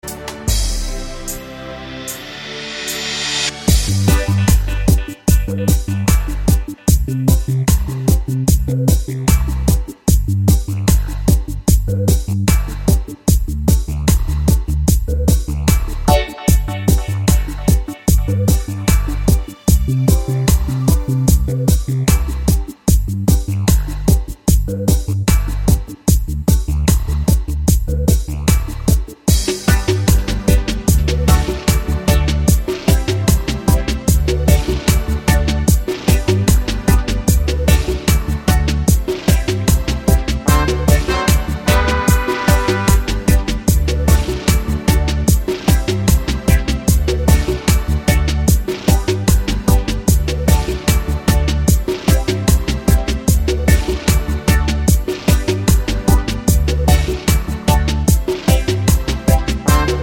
no Backing Vocals Reggae 3:26 Buy £1.50